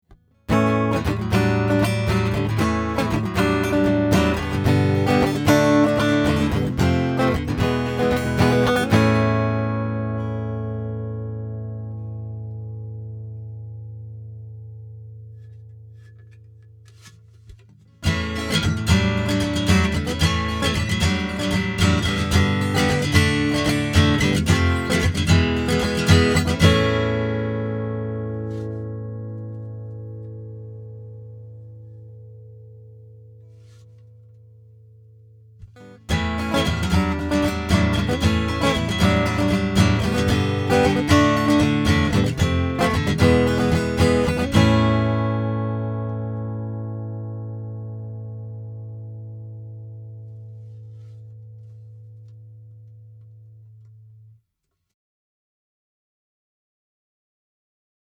The first three were recorded completely dry. No EQ, no compression. I play the same riff three times in each clip, varying the amount of condenser mic in each. The first part isolates the magnetic pickup with no condenser, the second part has the condenser opened up wide. The third part has the condenser mic set to about 50%.
Strum
faststrum.mp3